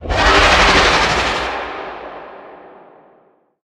Sfx_creature_iceworm_vo_disappear_02.ogg